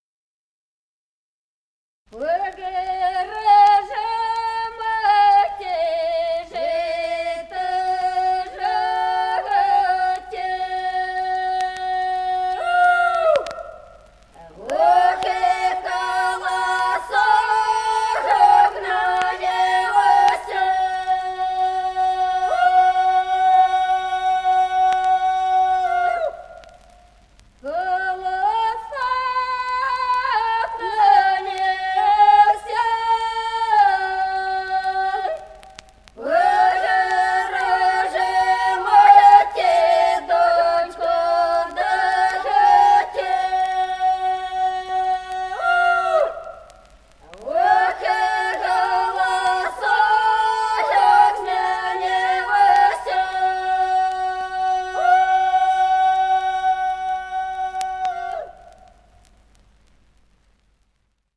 Народные песни Стародубского района «Пора же, мати, жито жати», жнивная.
1951 г., c. Остроглядово.